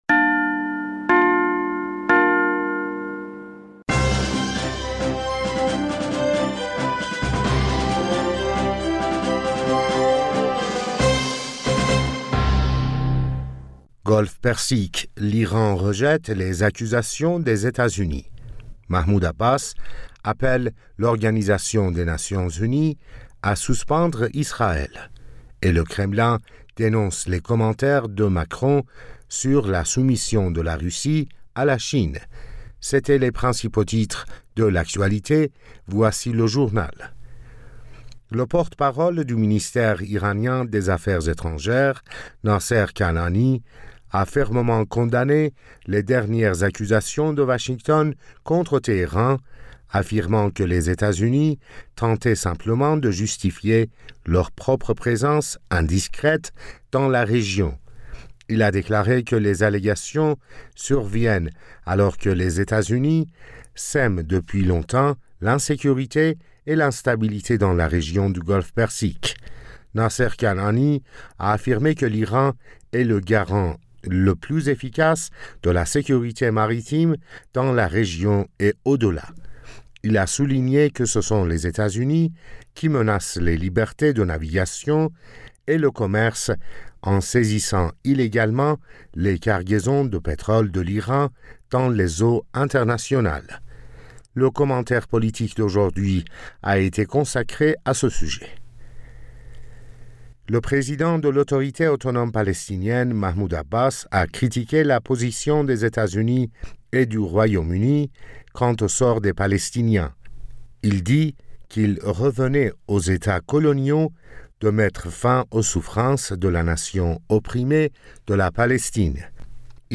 Bulletin d'information du 16 Mai 2023